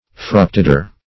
Fructidor \Fruc`ti`dor"\, n. [F., fr. L. fructus fruit.]